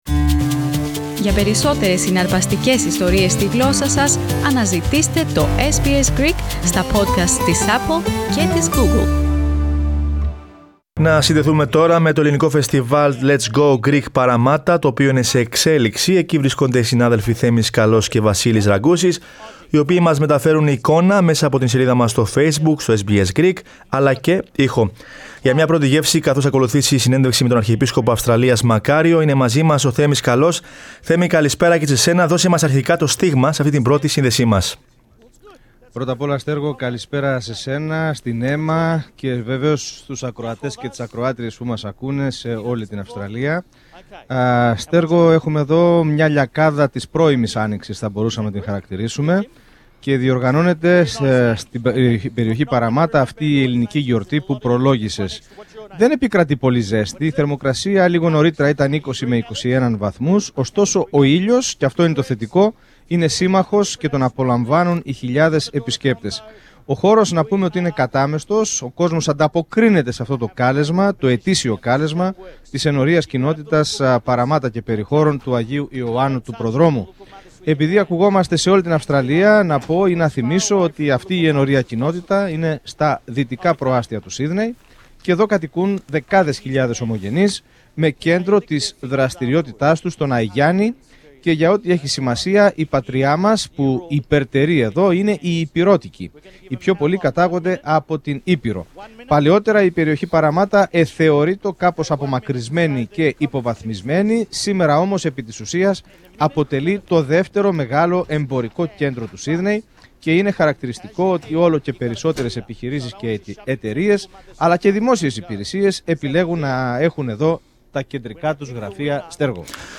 Στο Φεστιβάλ παρευρίσκονταν και η Μονάδα Εξωτερικών Μεταδόσεων της Ραδιοφωνίας SBS. Στην εκπομπή μας, 4-6 μ.μ. είχαμε ζωντανή σύνδεση με ανταπόκριση για τα διαδραματιζόμενα και μεταδώσαμε συνεντεύξεις με επισήμους.